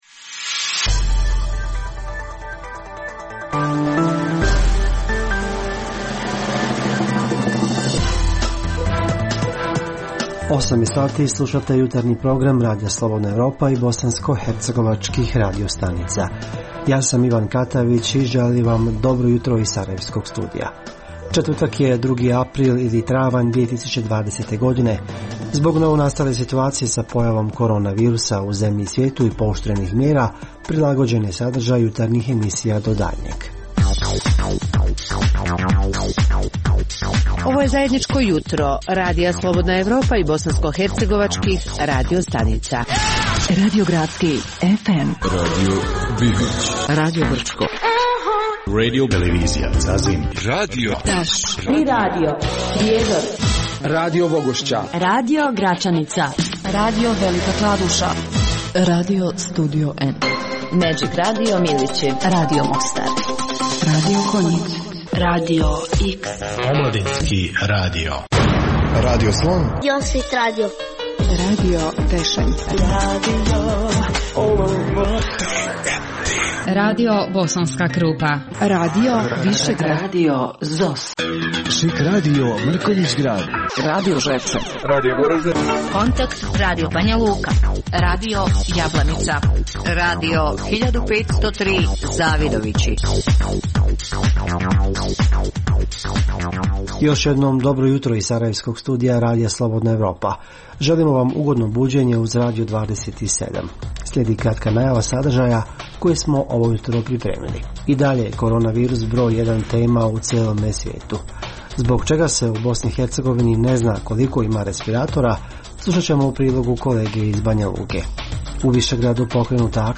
Jutarnji program za BiH koji se emituje uživo.
Redovni sadržaji jutarnjeg programa za BiH su i vijesti i muzika.